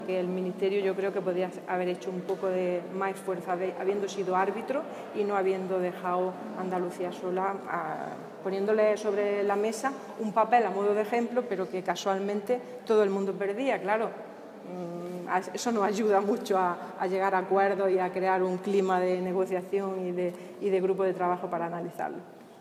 Declaraciones Carmen Ortiz postura Ministerio